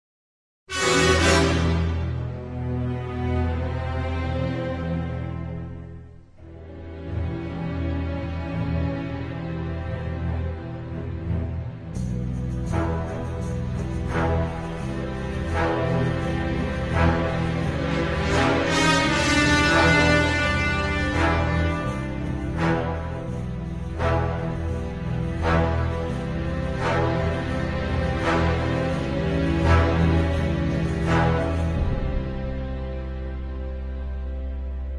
Intriga | Emocionario musical
Estas pezas musicais tratan de evocar intriga: